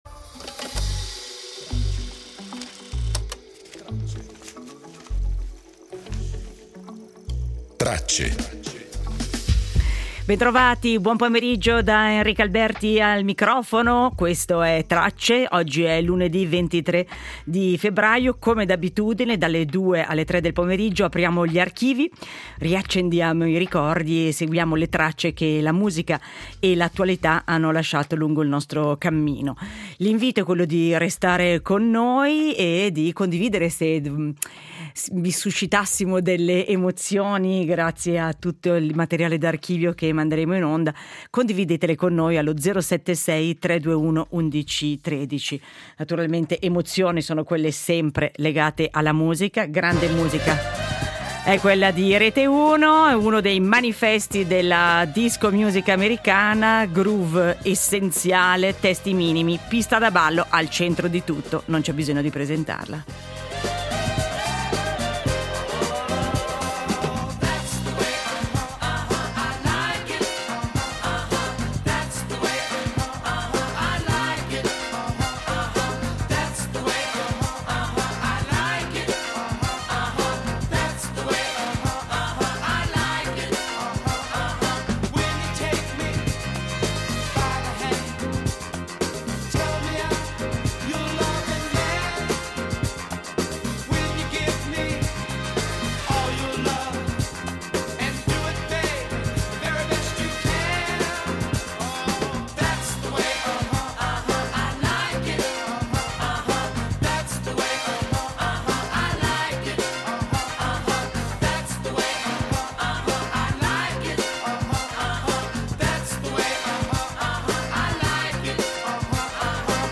Sentiamo le voci di alcuni ragazzi che nel 1982 vedevano l’anno 2000 alcuni come fosco, altri come forse inesistente e altri ancora identico al loro presente. Rievochiamo un piccolo spaccato della fine degli anni ‘60 e dell’arrivo di una serie americana che in chiave comica, domestica e paradossale metteva in scena una famiglia di mostri.